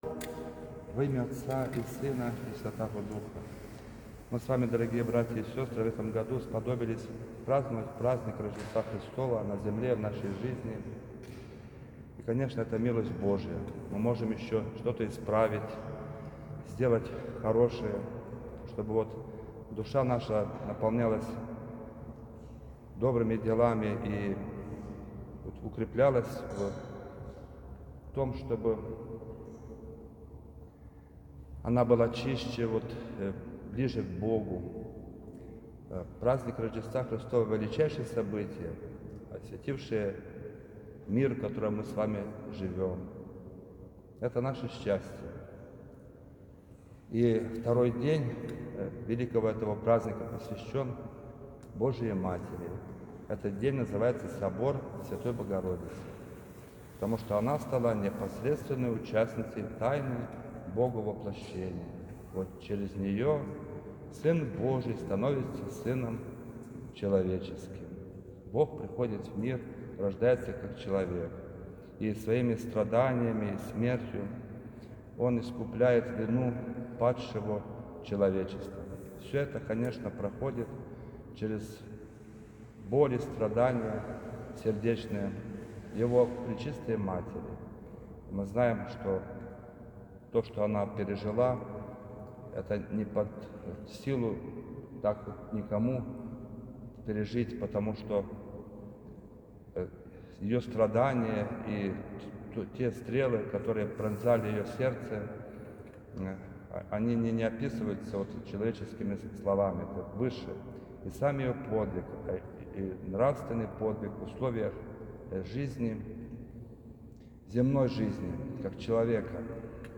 Проповедь.m4a